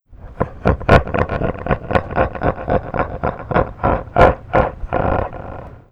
slenderlaugh.wav